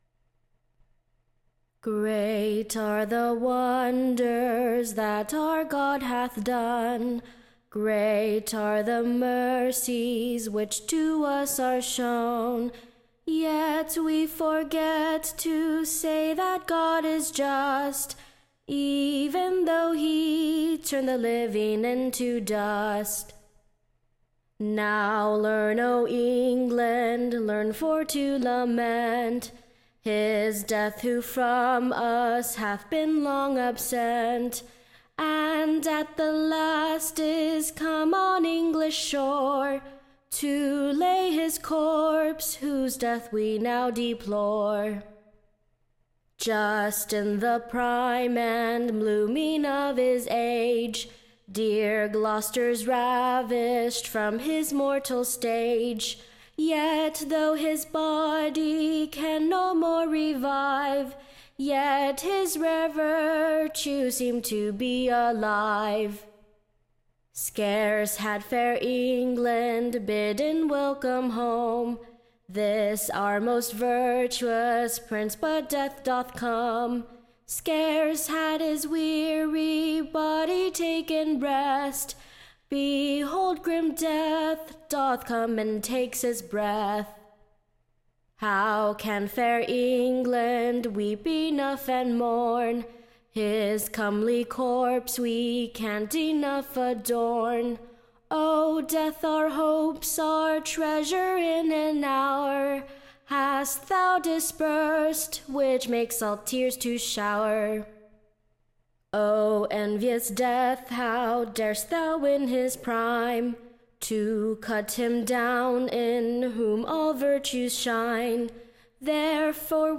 Recording Information Ballad Title Dying Tears.